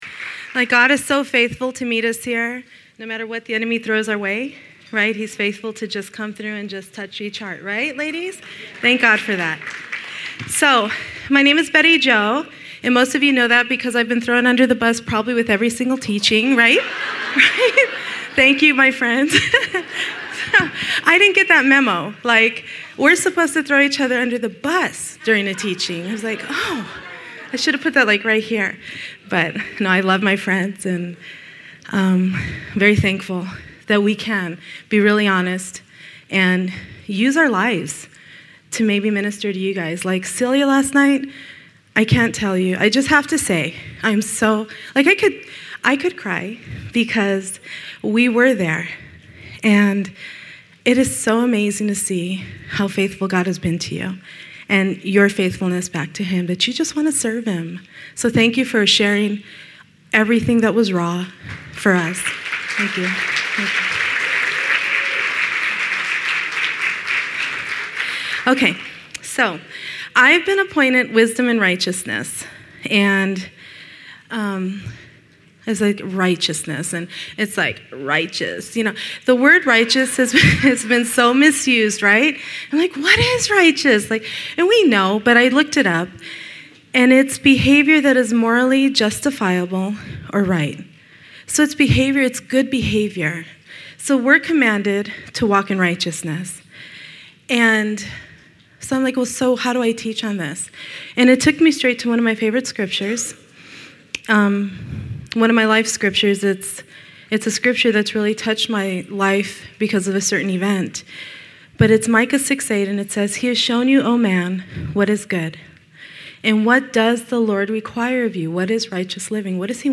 Women's Retreat 2015: Pearl's of Wisdom